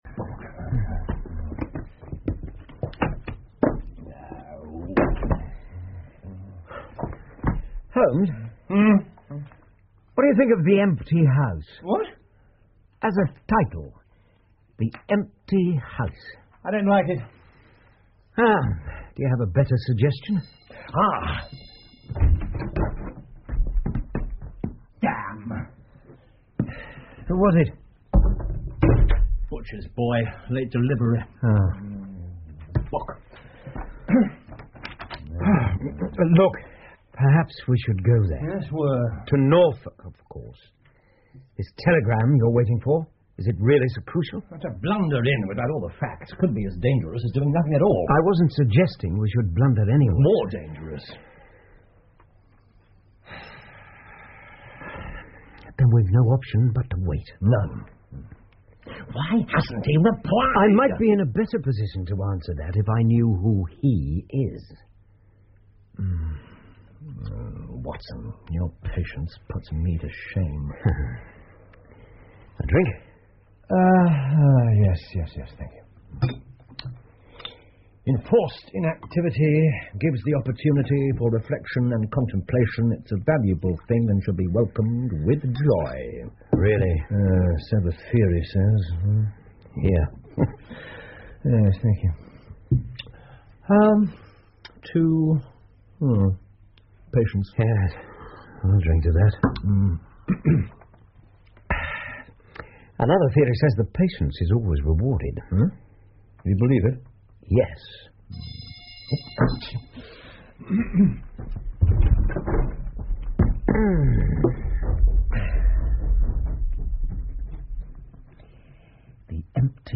福尔摩斯广播剧 The Dancing Men 7 听力文件下载—在线英语听力室